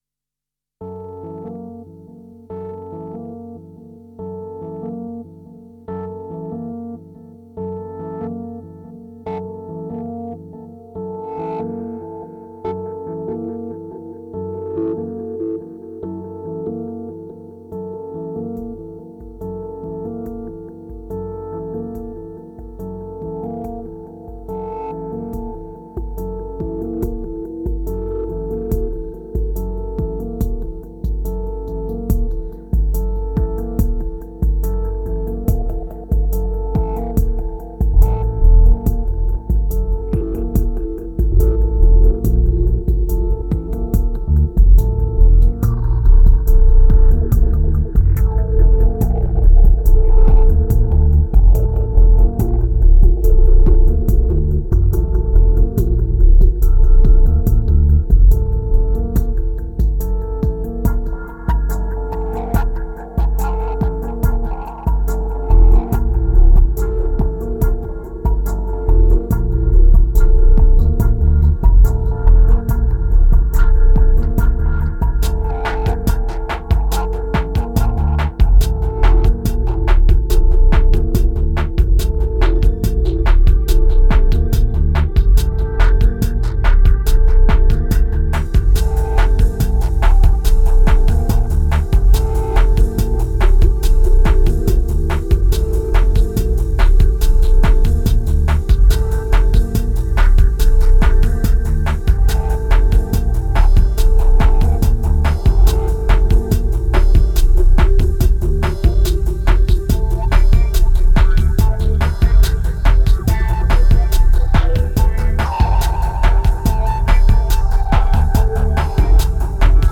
1999📈 - 39%🤔 - 71BPM🔊 - 2010-11-18📅 - -19🌟
Trip-hop